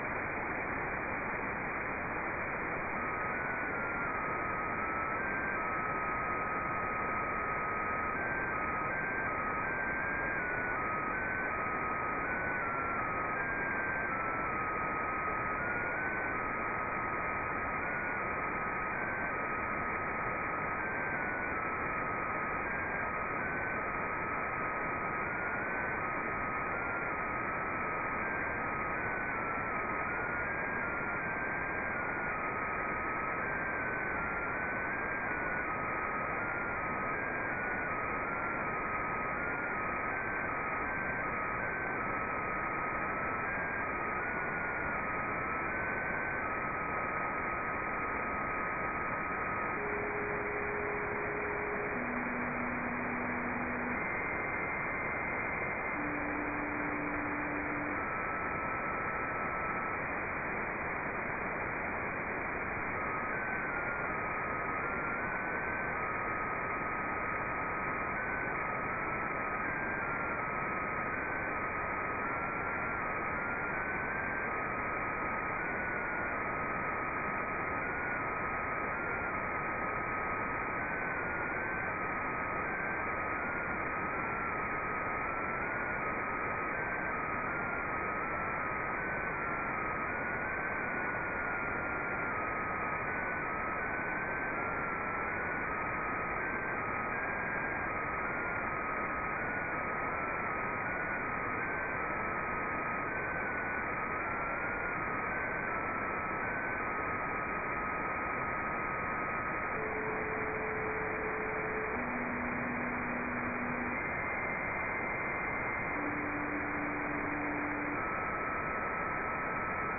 145.980 MHz mode JT65B
Five successive 1 minute sequences are sent during the 5 minutes cycle.
The following is decoded using WSJT9 and a 4M JT65B